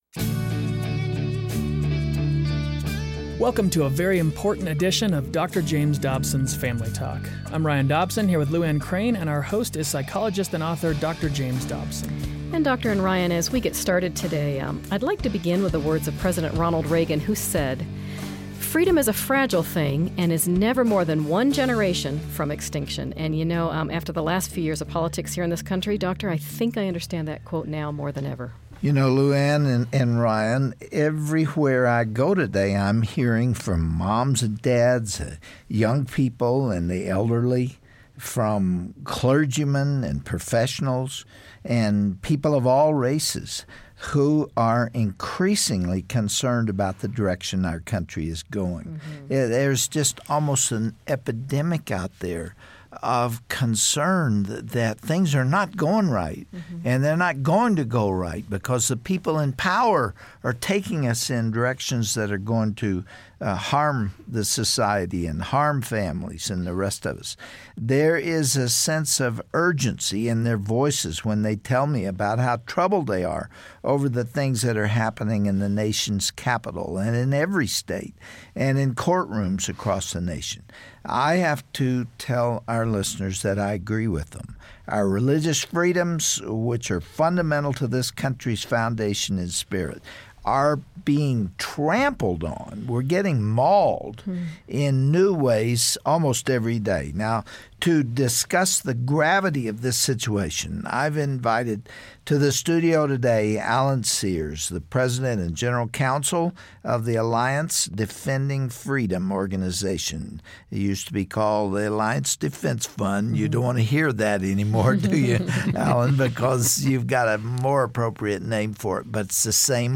Learn about laws being decided today that will affect your family tomorrow, and be encouraged by the great work being done. It's a discussion not to be missed!